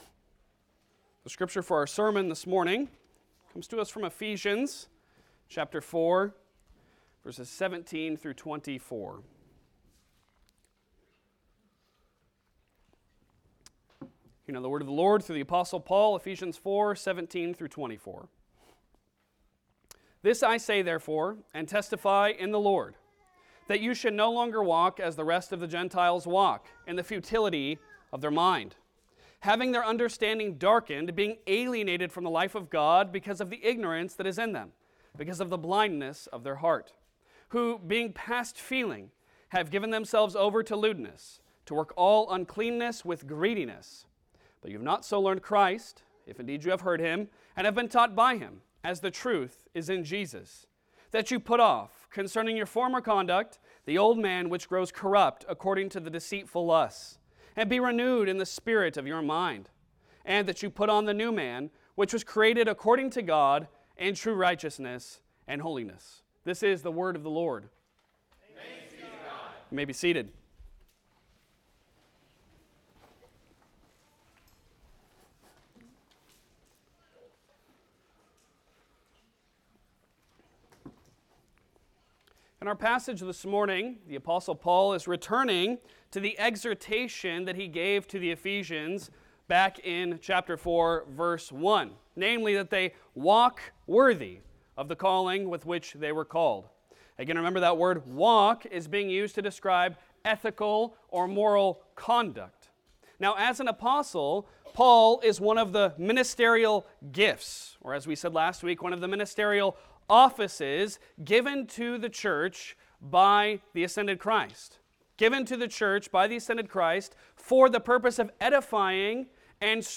Passage: Ephesians 4:17-24 Service Type: Sunday Sermon